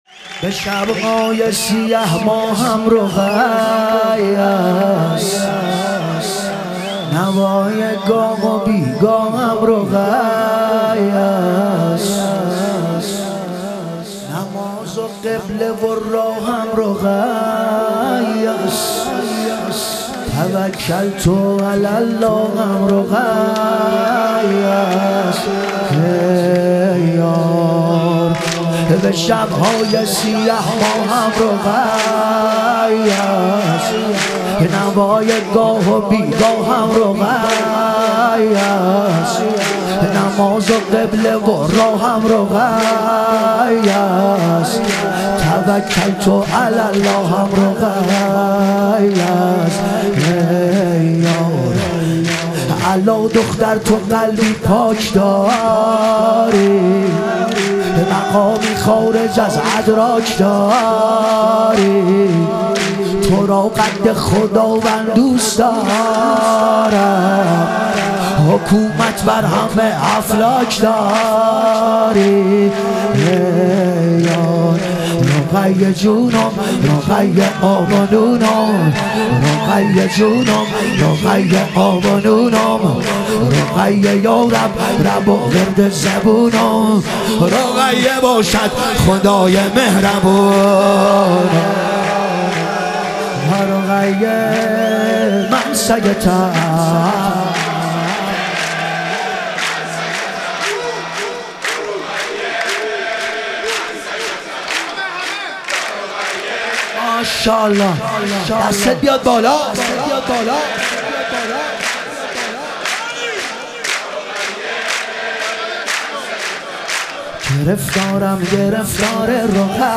ظهور وجود مقدس حضرت رقیه علیها سلام - تک